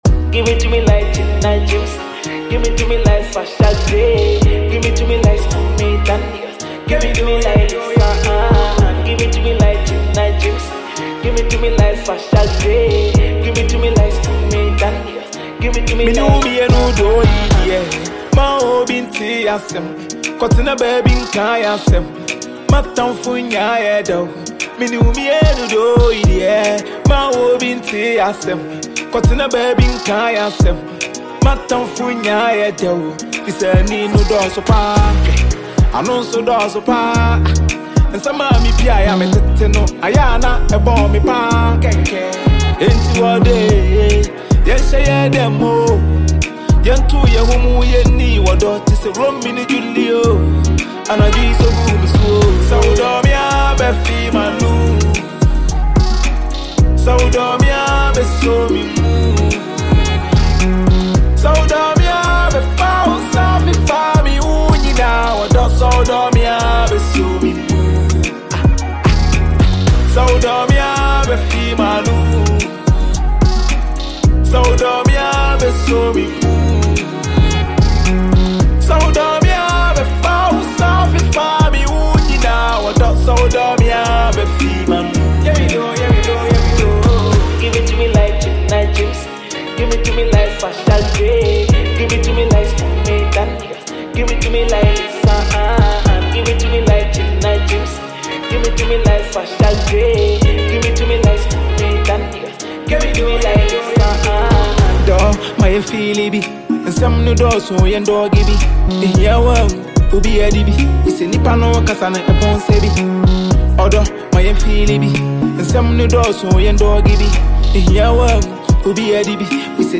It combines emotion and rhythm